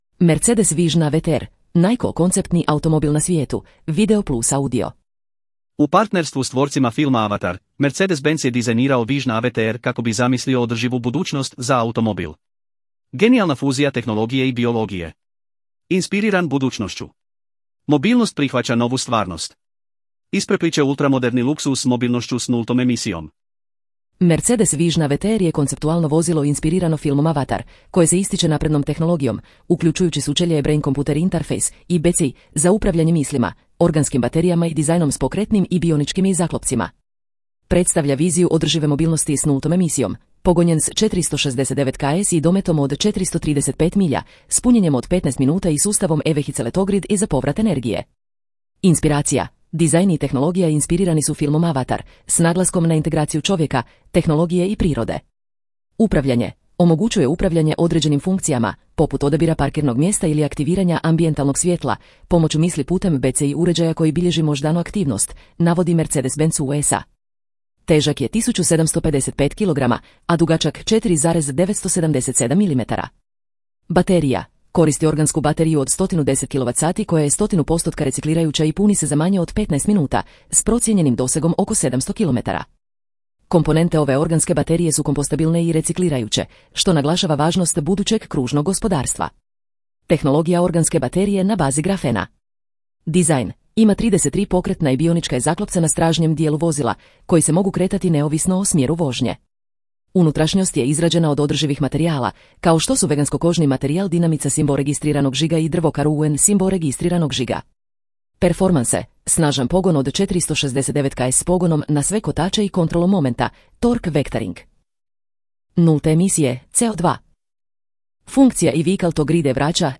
POSLUŠAJTE AUDIO SNIMKU OBJAVLJENOG TEKSTA U ČLANKU